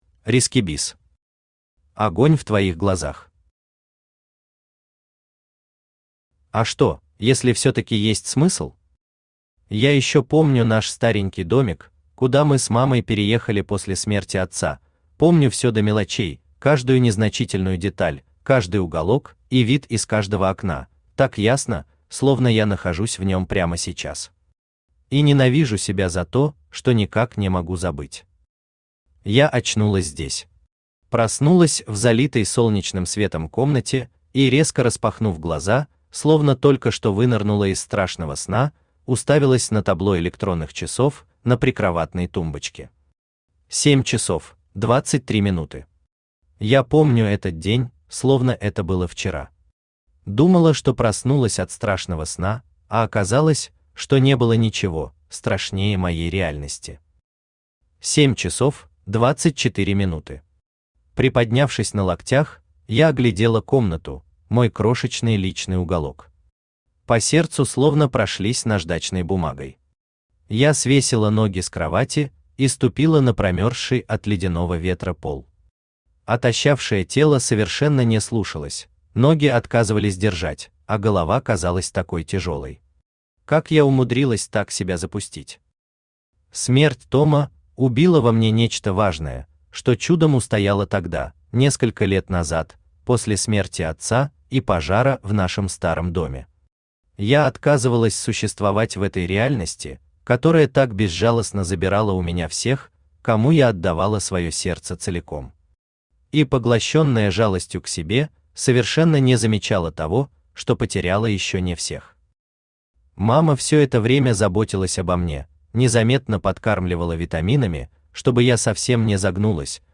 Aудиокнига Огонь в твоих глазах Автор RiskyBiz Читает аудиокнигу Авточтец ЛитРес.